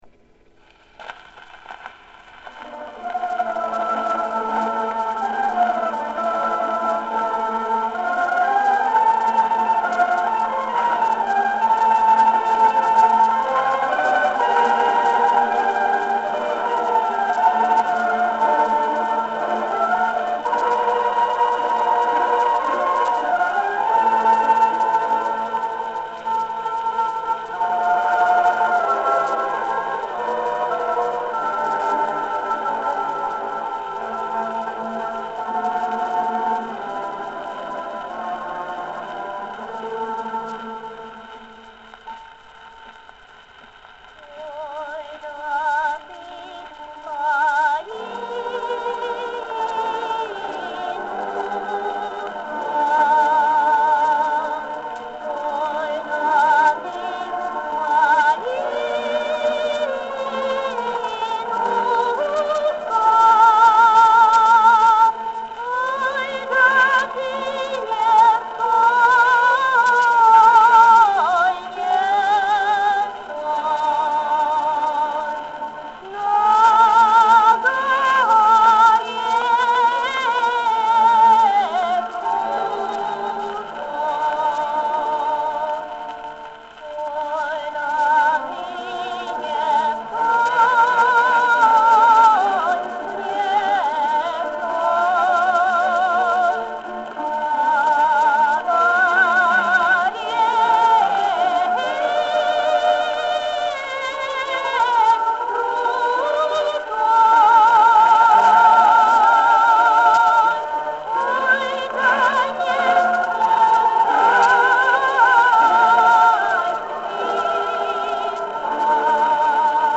сделанную с граммофонной пластинки.
Русскую народную песню «Ой да ты